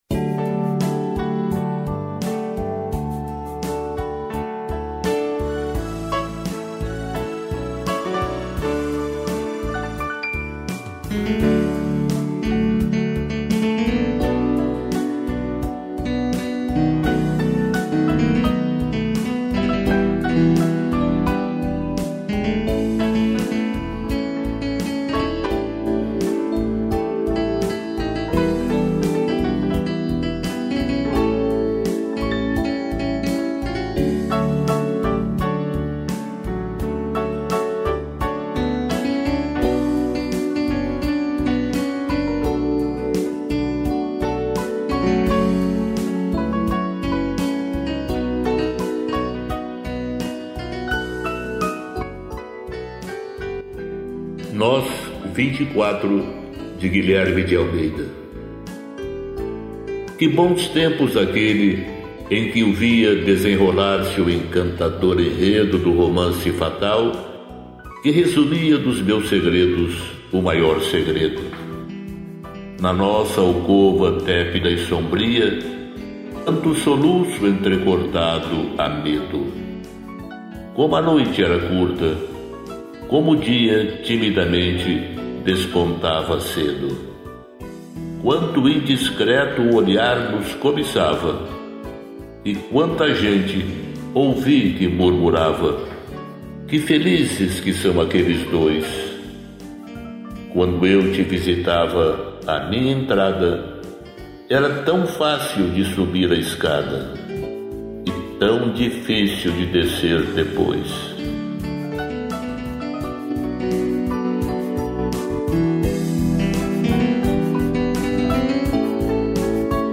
piano, sax e strings